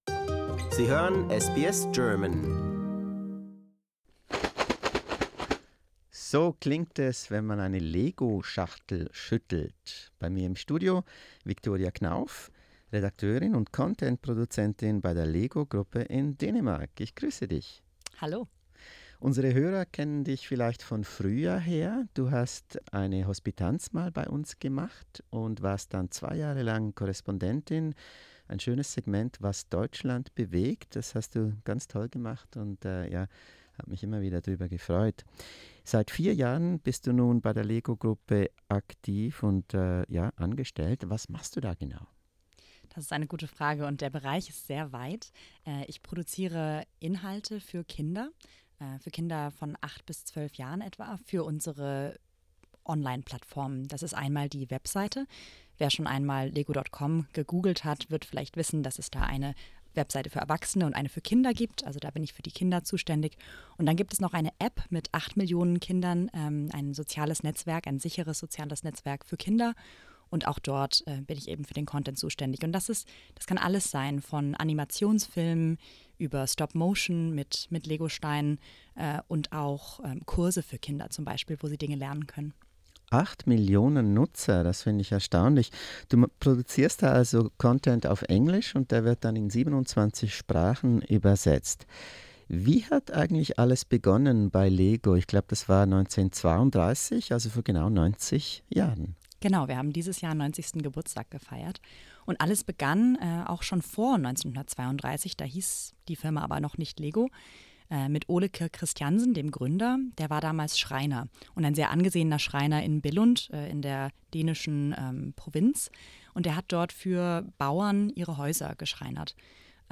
But did you know that the Danish family business almost went bankrupt in 2003? This and more, in an enthralling SBS interview.